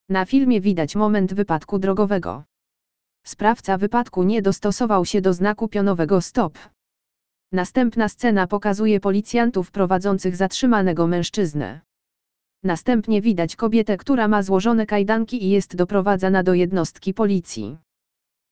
opis do filmu - plik wav
audodeskrypcjadofilmu.wav